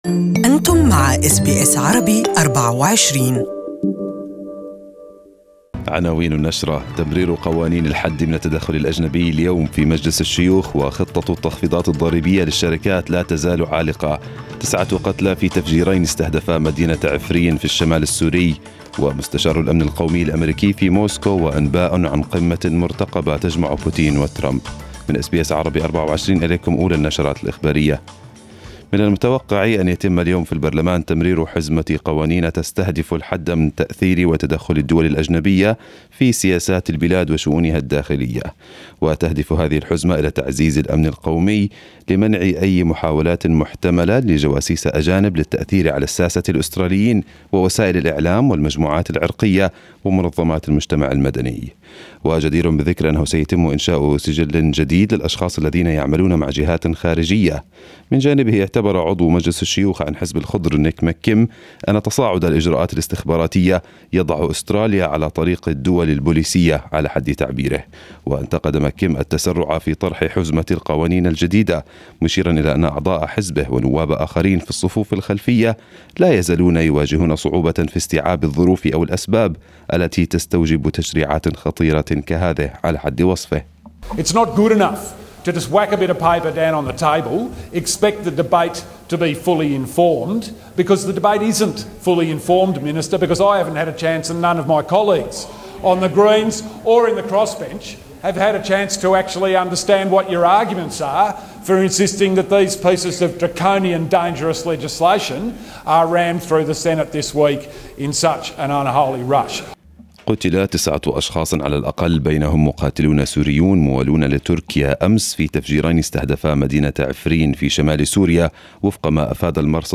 Arabic News Bulletin 28/06/2018